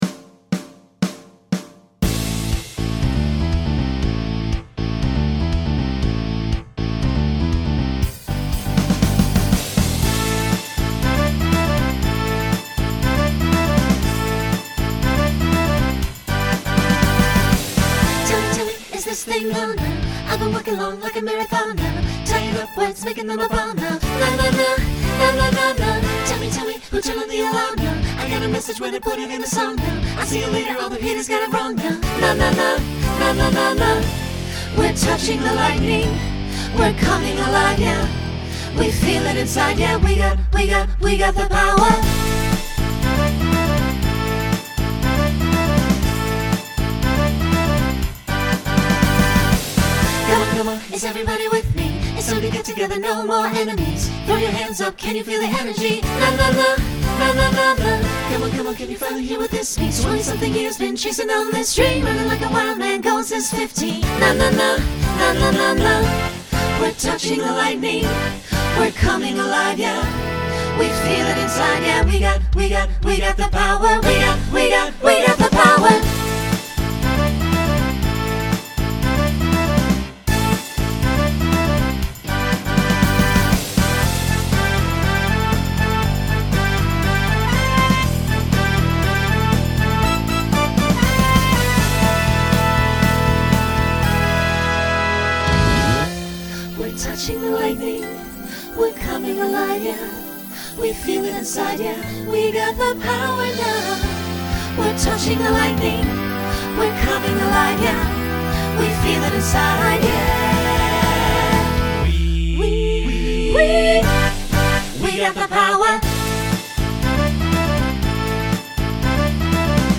New SAB voicing for 2025.